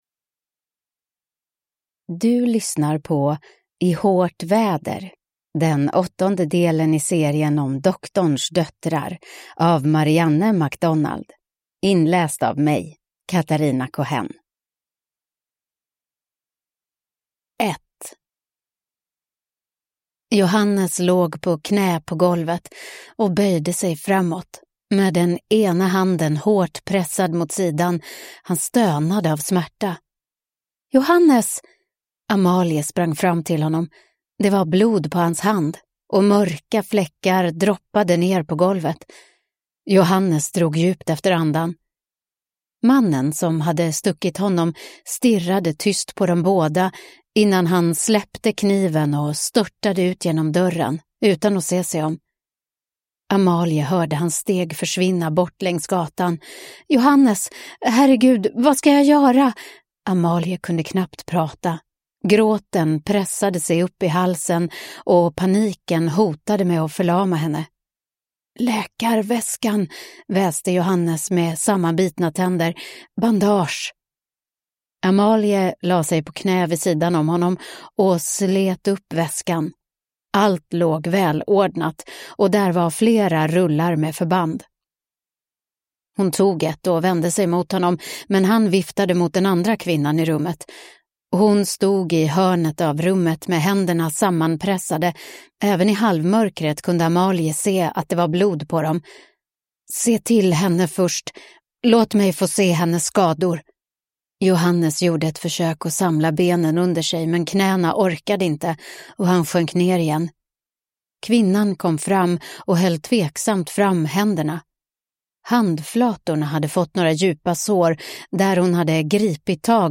I hårt väder (ljudbok) av Marianne MacDonald